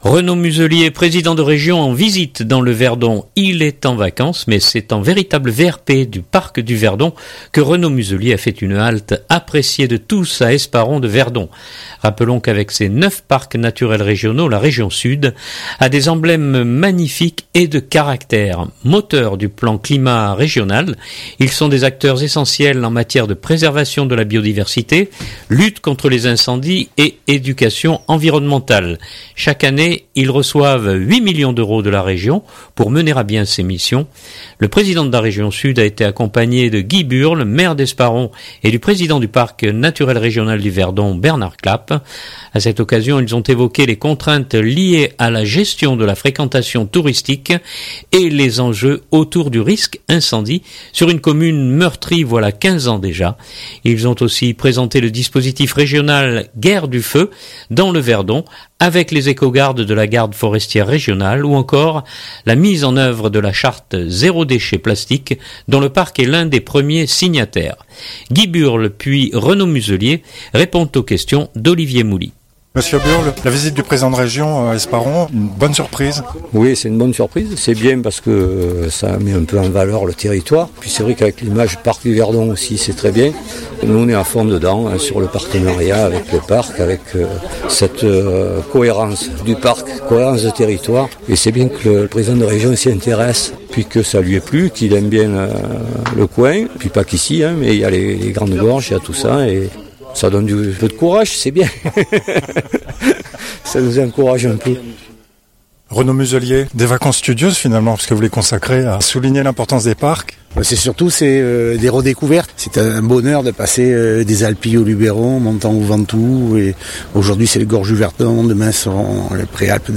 répondent aux questions